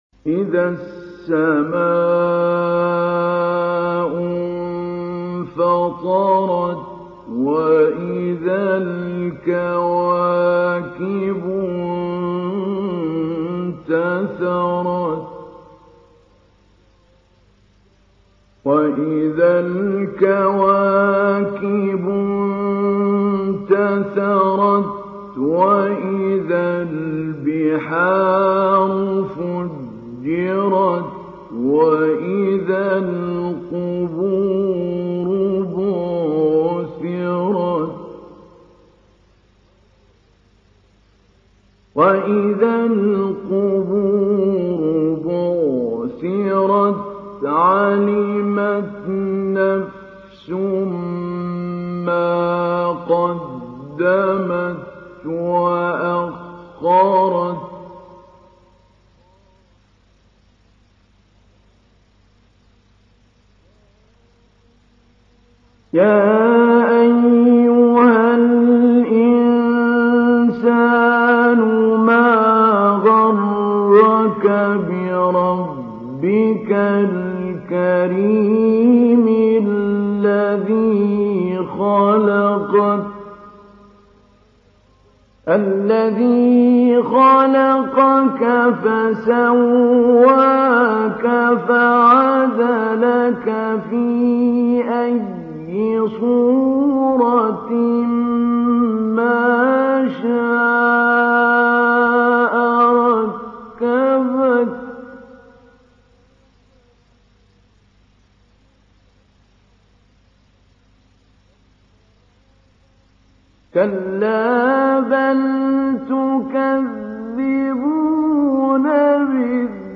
تحميل : 82. سورة الانفطار / القارئ محمود علي البنا / القرآن الكريم / موقع يا حسين